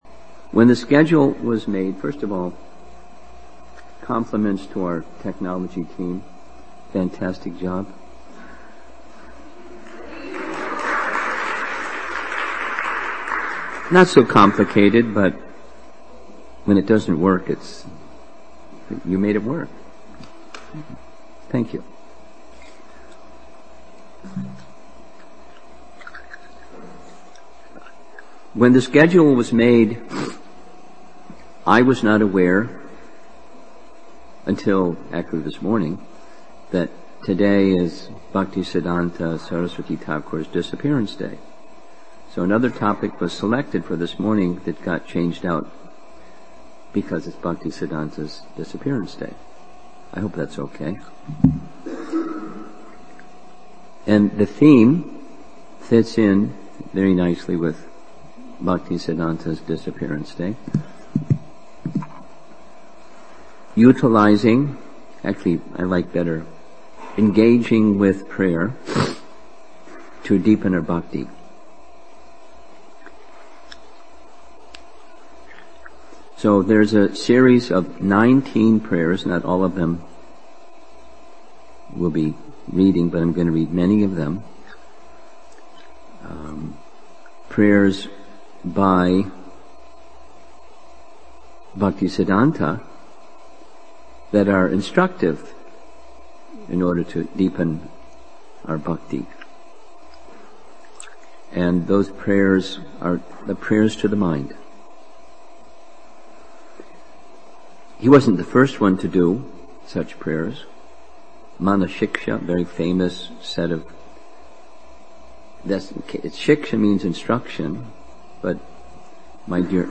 Bhaktisiddhanta Sarasvati Thakuras disappearance day – Session 13 Winter Retreat Chicago December 2018